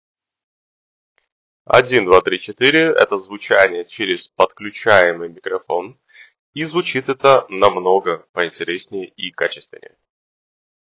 Съемный микрофон — хороший.
В тихих условиях — через комплектный микрофон: